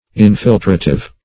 Infiltrative \In*fil"tra*tive\, a. Of or pertaining to infiltration.